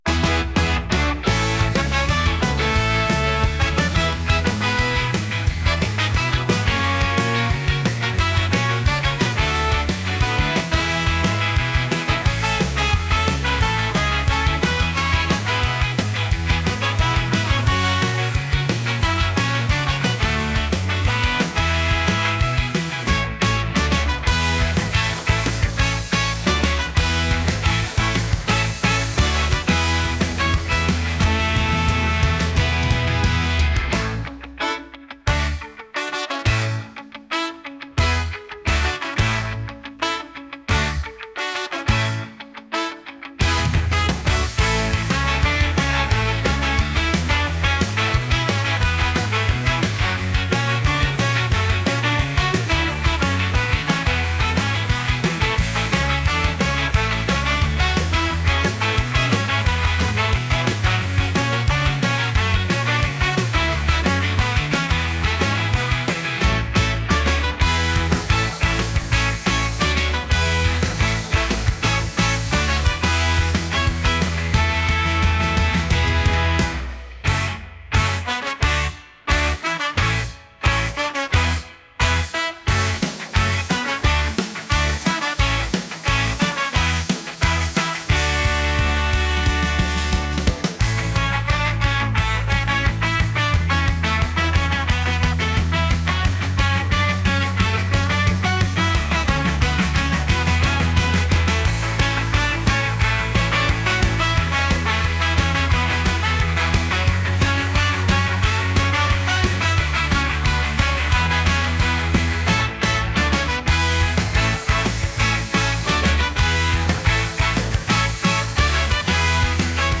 ska | punk